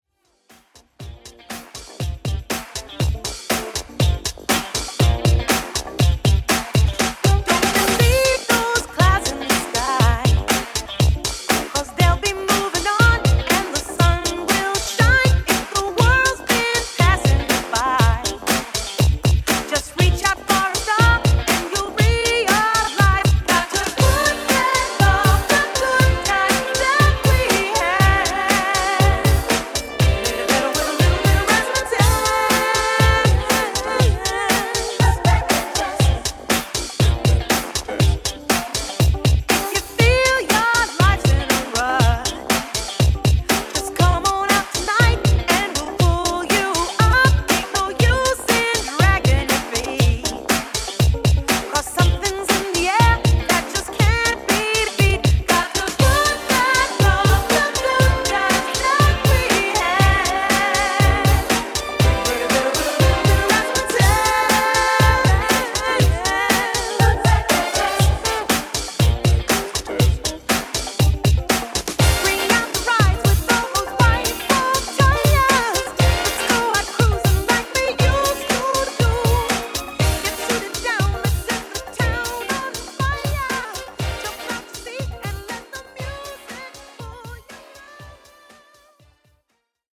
80s Redrum)Date Added